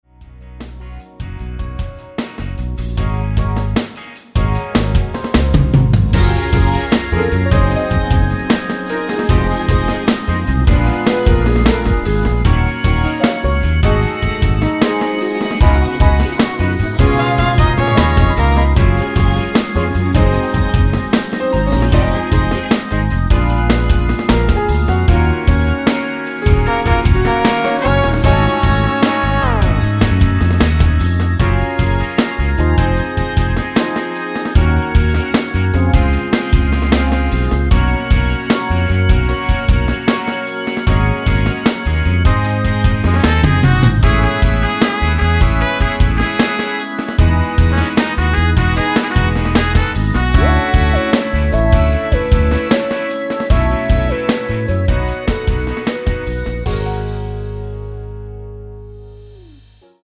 원키 코러스 포함된 MR 입니다.(미리듣기 참조)
F#
앞부분30초, 뒷부분30초씩 편집해서 올려 드리고 있습니다.
중간에 음이 끈어지고 다시 나오는 이유는